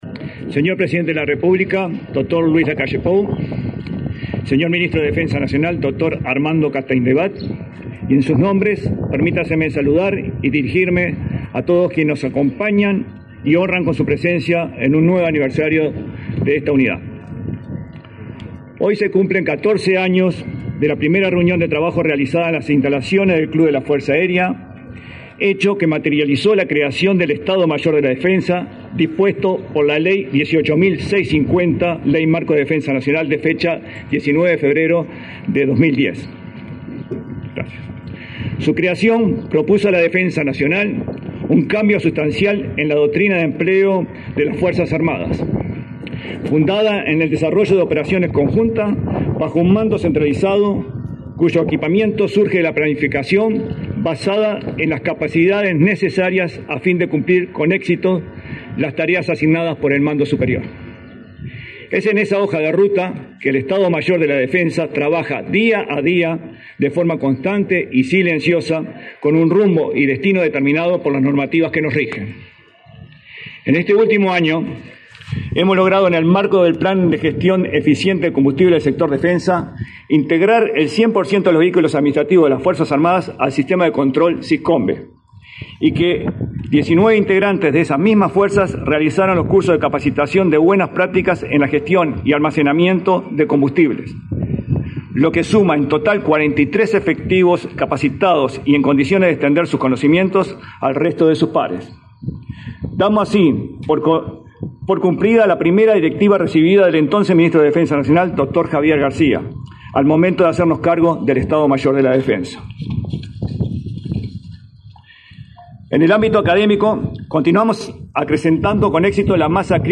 Palabras del jefe del Esmade, Rodolfo Pereyra
Palabras del jefe del Esmade, Rodolfo Pereyra 22/10/2024 Compartir Facebook X Copiar enlace WhatsApp LinkedIn En el marco de la ceremonia conmemorativa del 14.° aniversario del Estado Mayor de la Defensa (Esmade), se expresó el jefe de esa dependencia, Rodolfo Pereyra.